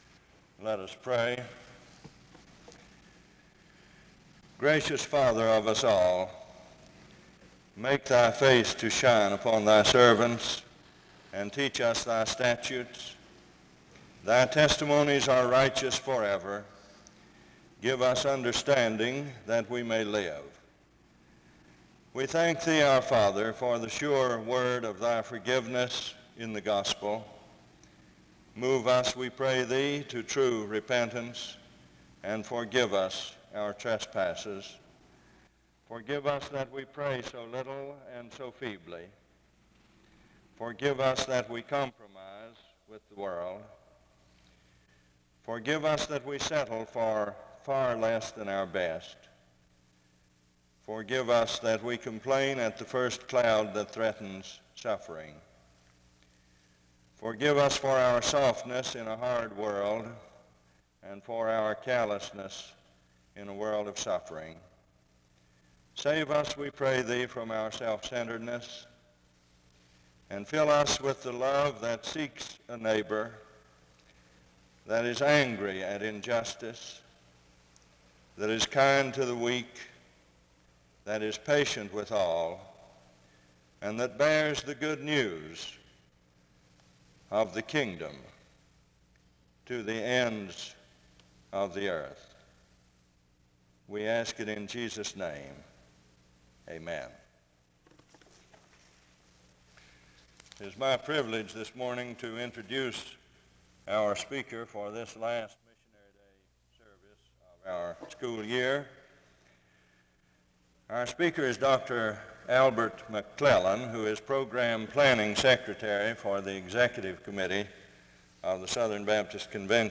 The service starts with a word of prayer from 0:00-1:37.